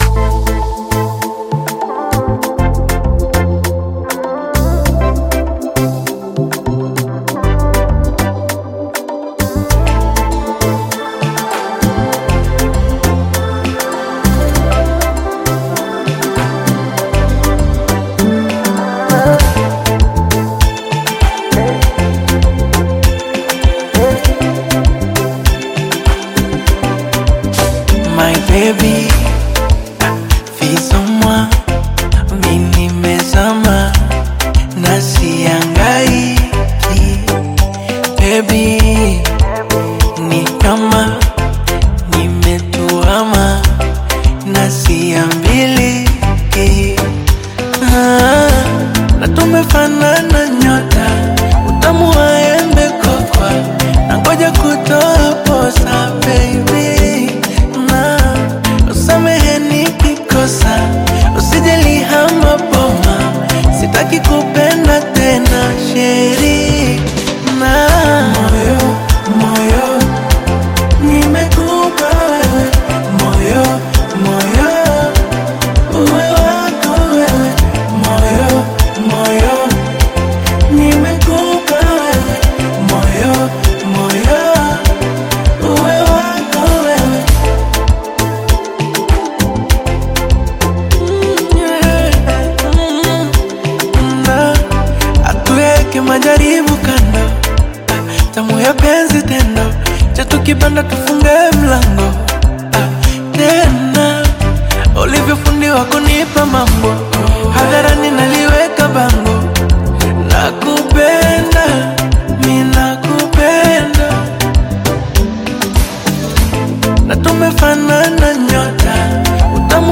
Bongo Flava music track